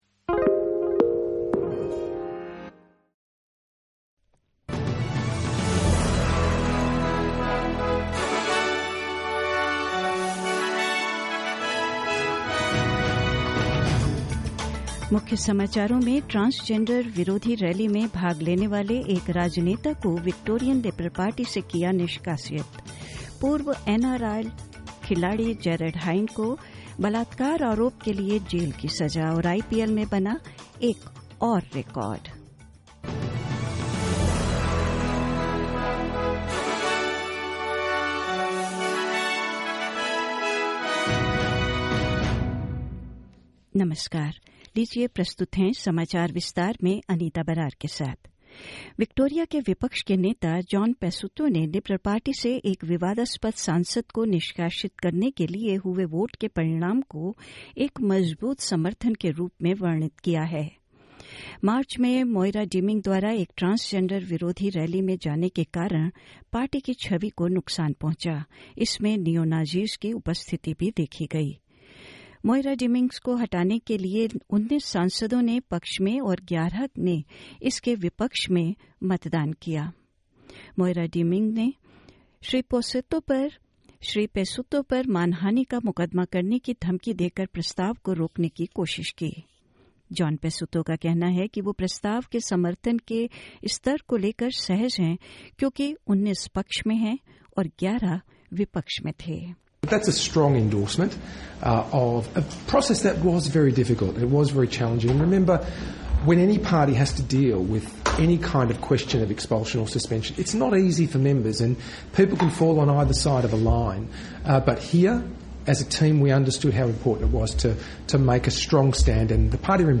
In this latest Hindi bulletin: Police clash with neo-Nazis and counter protesters outside Victoria's parliament; A missing surfer feared dead after a shark attack in South Australia; In golf, Australia's Sarah Kemp has a share of the lead at the LPGA Tour's Founders Cup in New Jersey and more news.